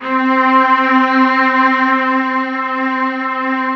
Strings (3).wav